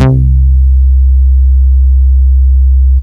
Bass
classic cx5 high.wav